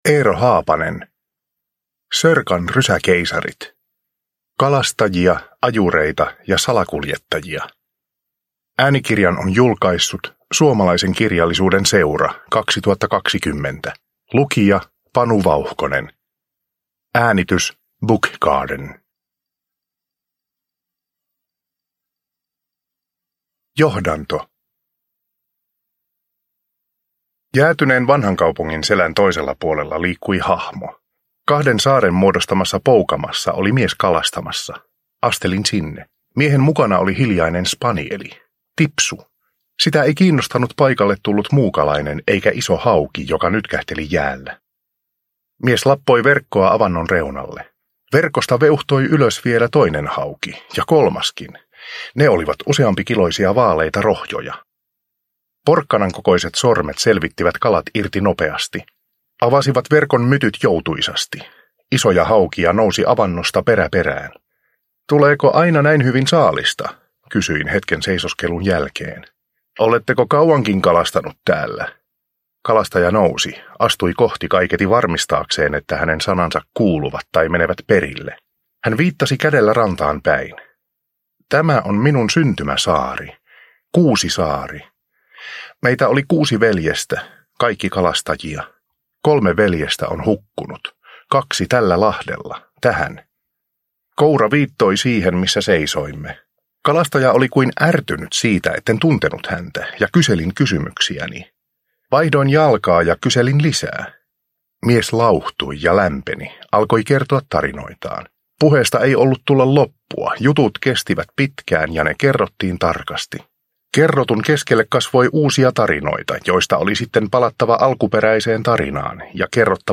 Sörkan rysäkeisarit – Ljudbok – Laddas ner